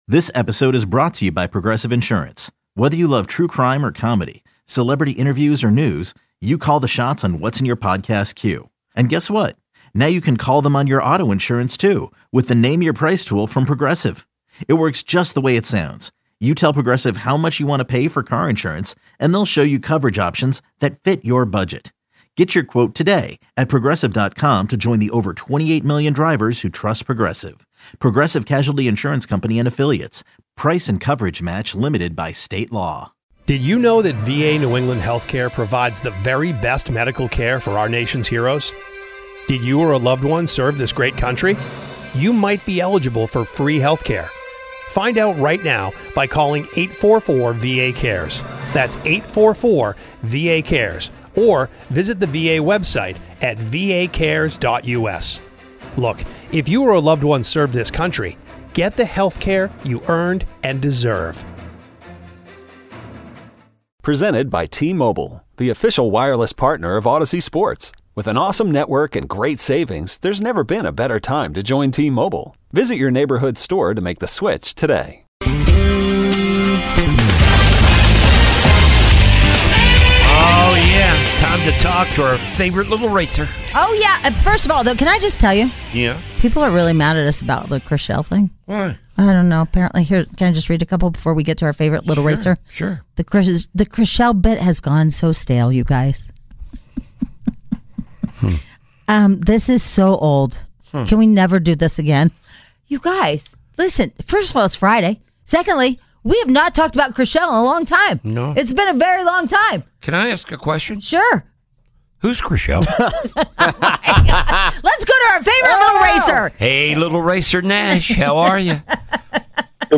Although, we noticed he sounds defeated..